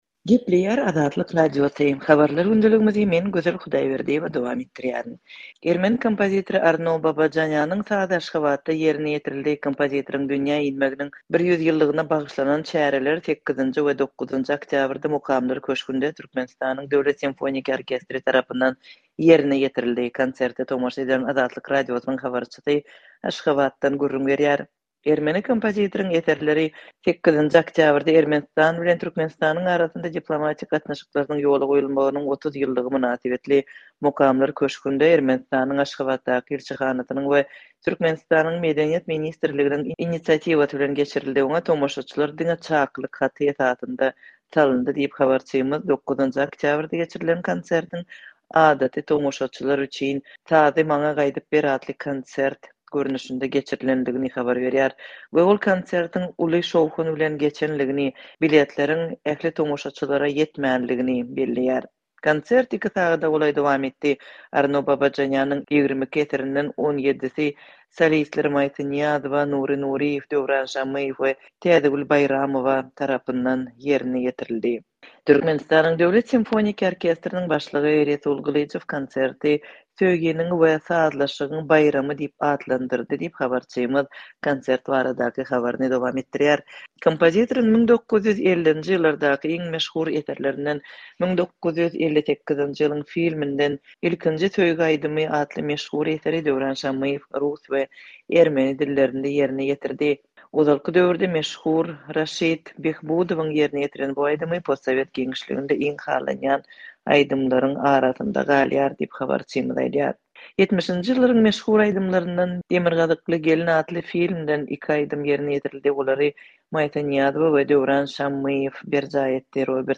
Kompozitoryň dünýä inmeginiň 100 ýyllygyna bagyşlanan konsert 8-nji we 9-njy oktýabrda Mukamlar köşgünde Türkmenistanyň Döwlet simfoniki orkestri tarapyndan ýerine ýetirildi. Konserte tomaşa eden Azatlygyň habarçysy Aşgabatdan gürrüň berýär.